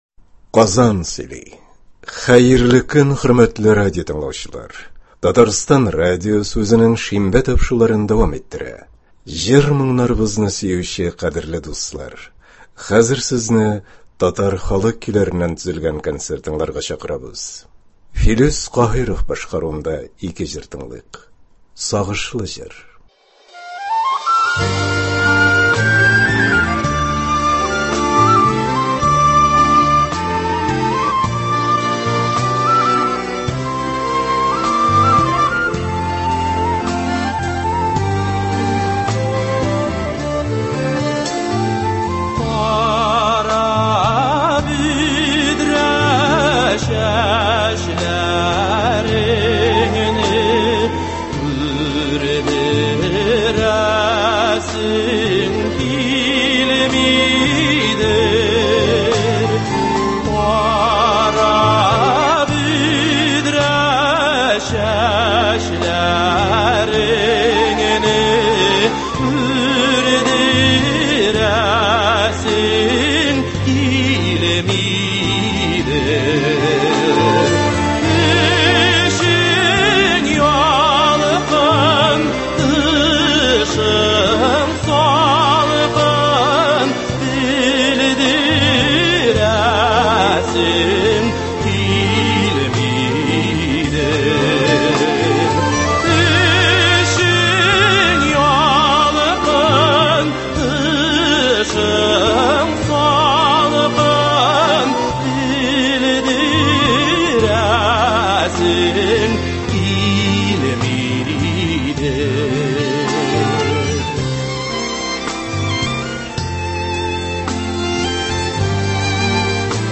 Татар халык җырлары (23.10.21)
Бүген без сезнең игътибарга радио фондында сакланган җырлардан төзелгән концерт тыңларга тәкъдим итәбез.